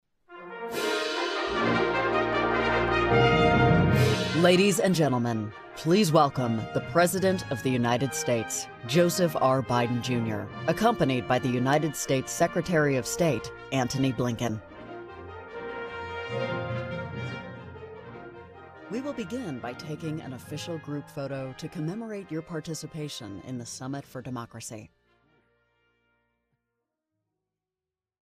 Female
Adult (30-50)
Voice Of God/Live Announcer